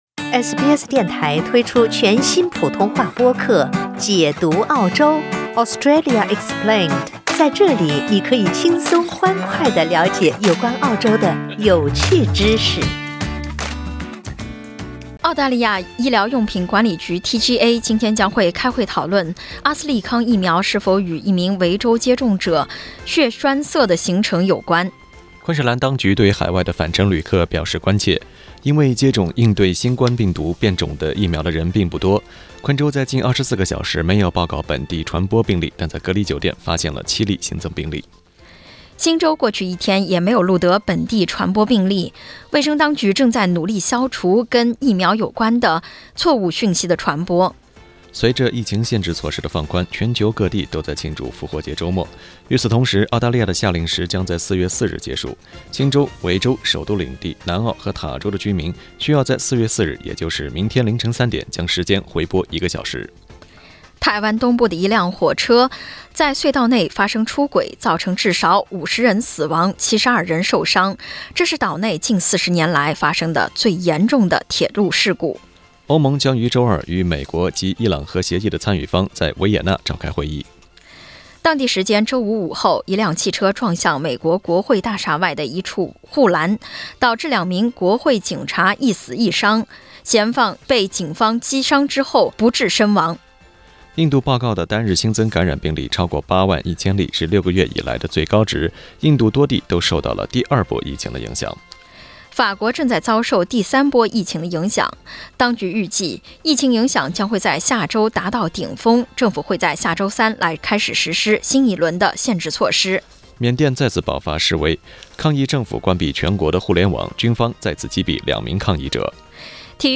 SBS早新聞（4月3日）